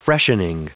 Prononciation du mot freshening en anglais (fichier audio)
Prononciation du mot : freshening